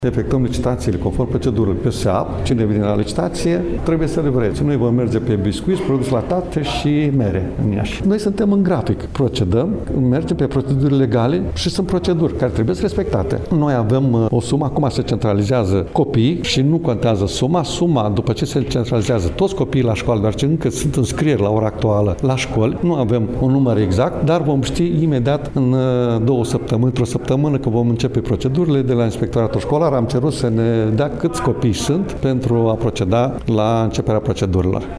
Președintele Maricel Popa a dat asigurări, astăzi, în cadrul ședinței CJ că în prima zi de școală, elevii vor avea la dispoziție produsele respective.